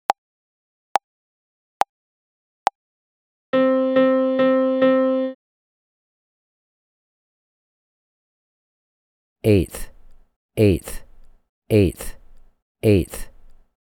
• Level 1: Half, Dotted Half, Quarter Note Rhythms in 4/4.
Find examples below for each level of the voice answer MP3s: